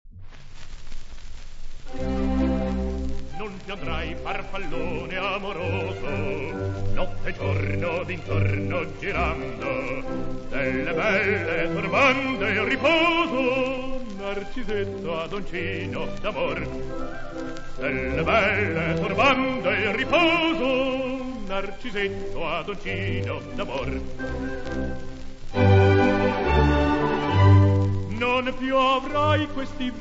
• Philharmonia Orchestra [interprete]
• Gobbi, Tito [interprete]
• arie
• opere buffe
• Opera buffa
• registrazione sonora di musica